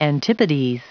Prononciation du mot antipodes en anglais (fichier audio)
Prononciation du mot : antipodes